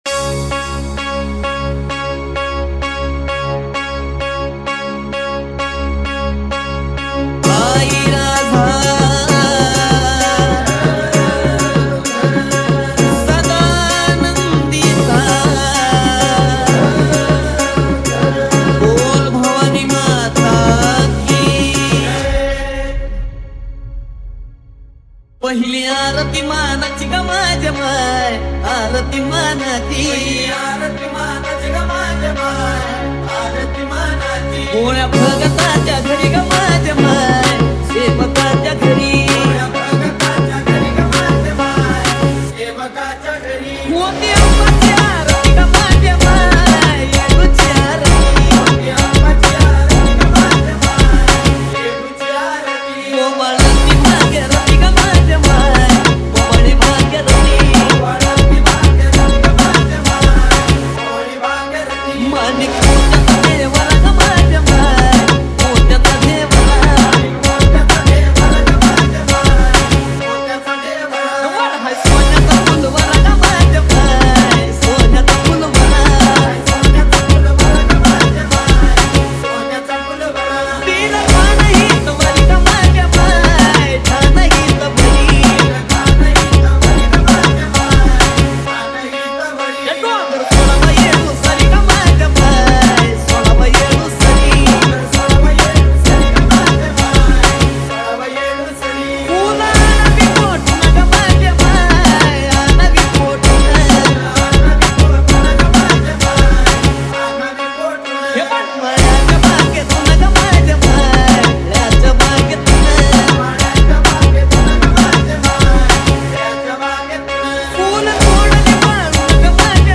• Category: MARATHI SOUND CHECK